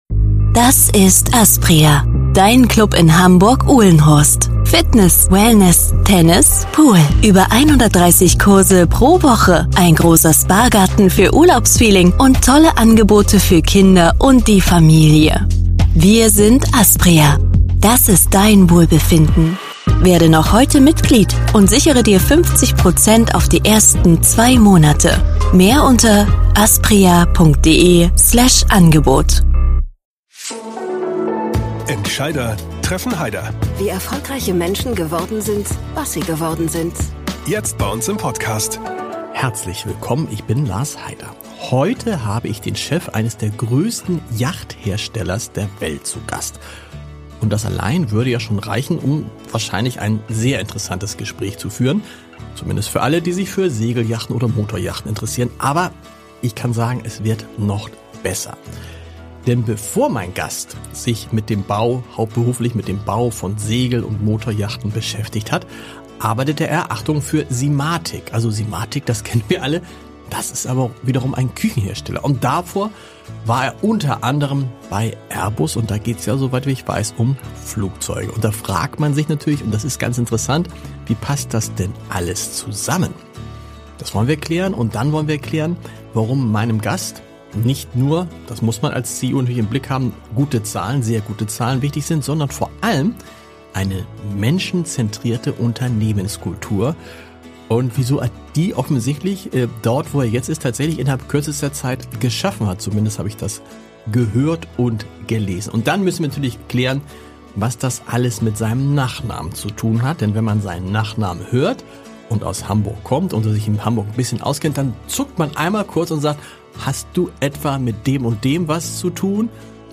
Willkommen zum Hamburger Abendblatt Interview-Podcast